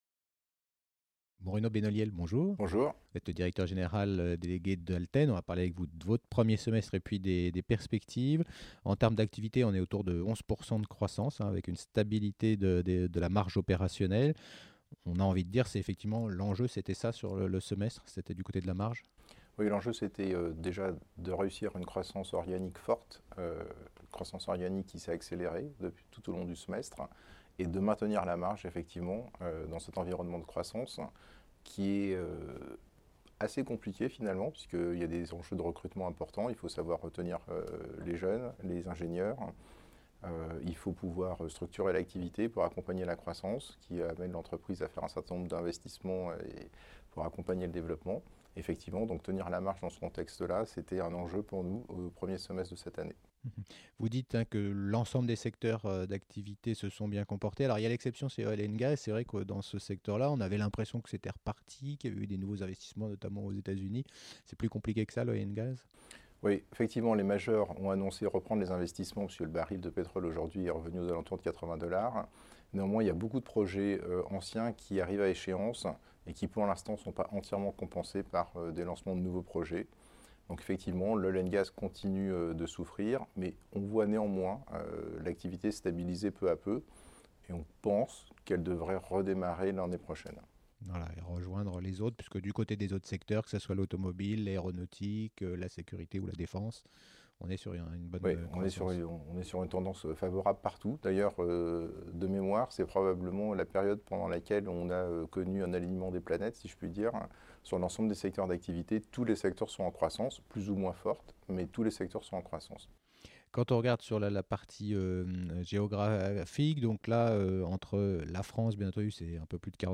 Vous vous intéressez à la société Alten, retrouvez toutes les interviews déjà diffusées sur la Web Tv via ce lien : Vidéo Alten